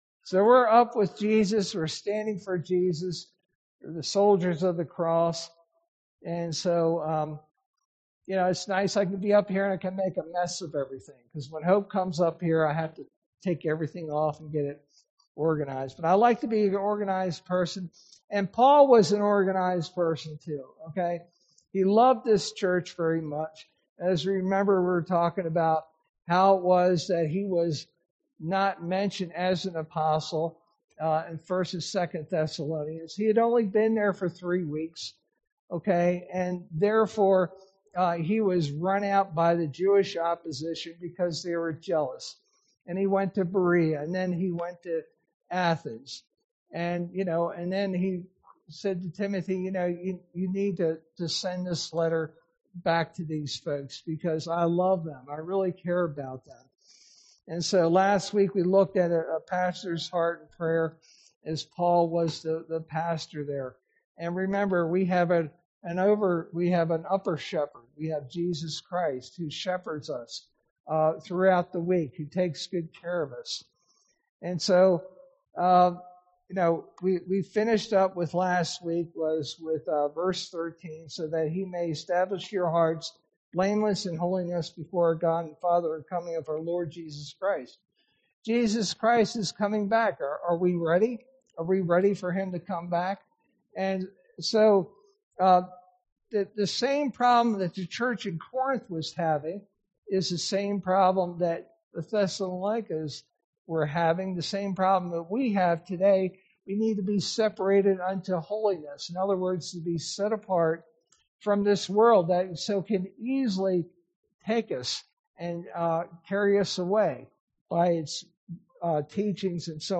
sermon verse:1 Thessalonians 4:1-12